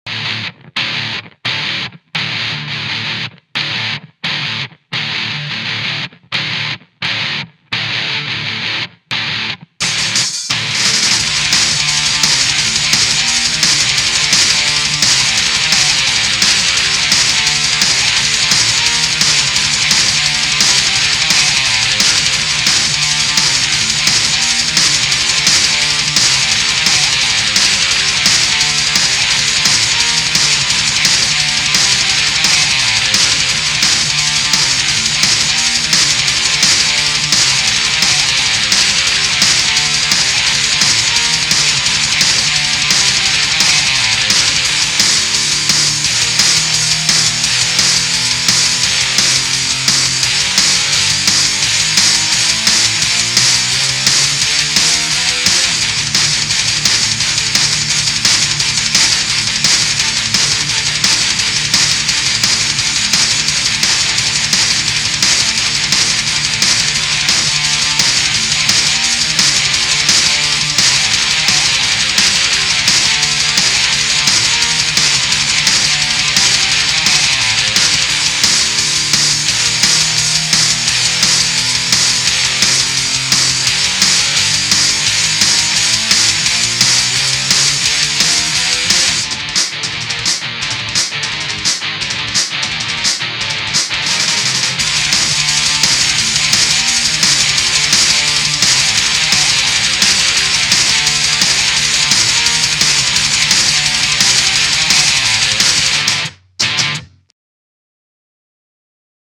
. here i am. took what advice was given to me and tried to incorporate it into the mix, curious if anyone could tell me if anything is still messed up. there was bass in it but i decided to drop the bass cause it seemed unneeded.